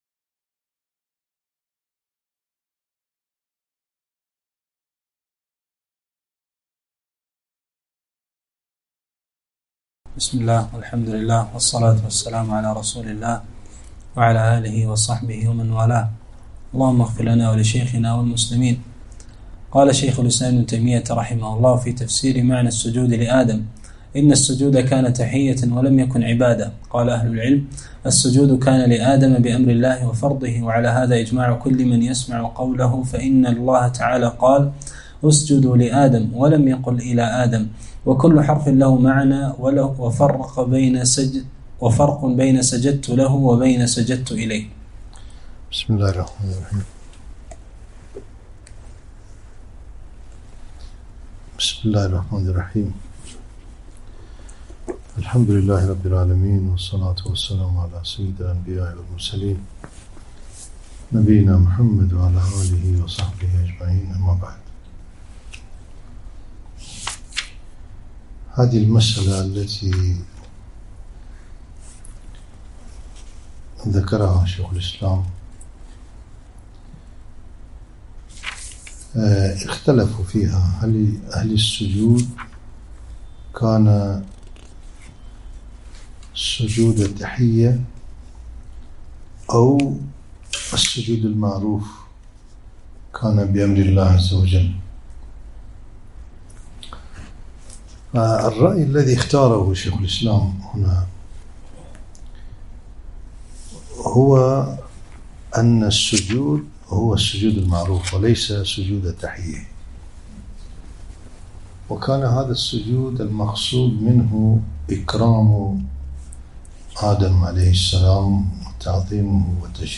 (17) تفسير شيخ الإسلام ابن تيمية - الدرس السابع عشر